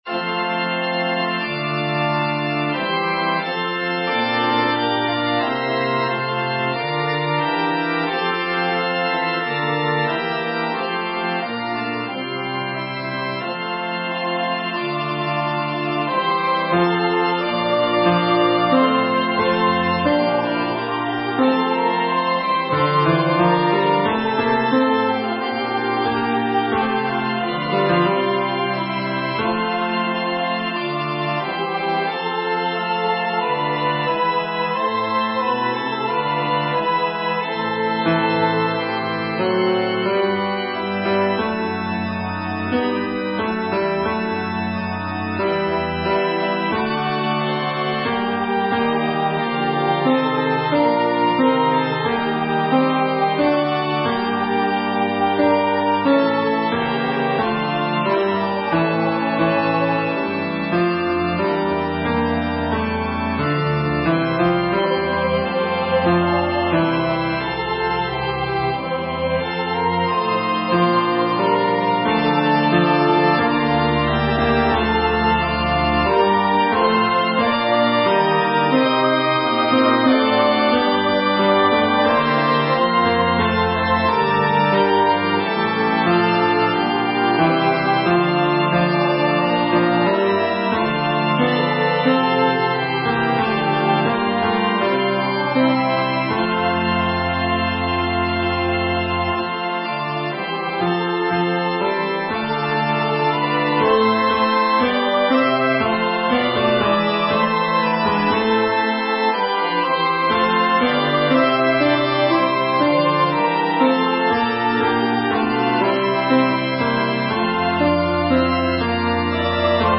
(SA TB Version):
Tenor/Bass:
Genre: SacredMotet
Instruments: Organ